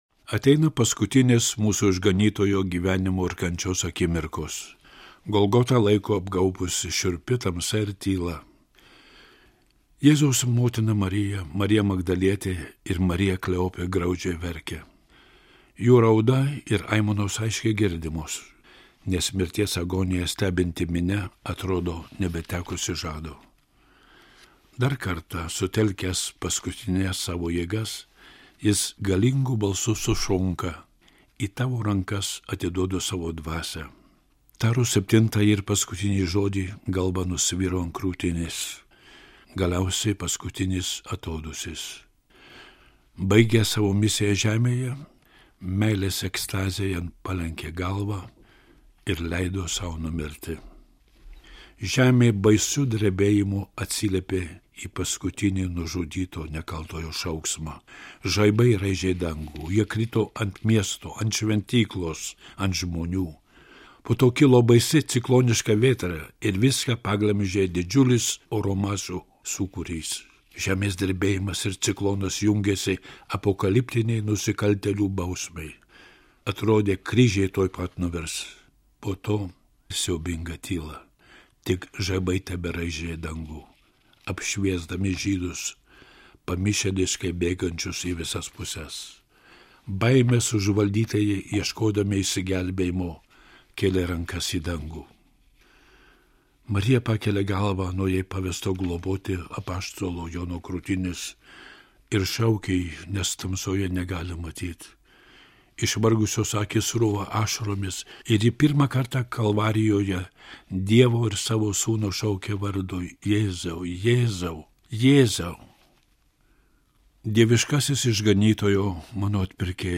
skaitovas / narrator